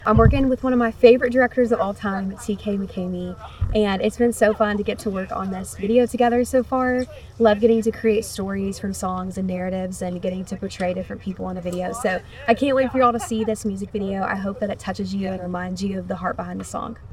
On the set of the music video for her single "Rain In The Rearview," Anne Wilson says she loved getting to work with her director and able to play different characters.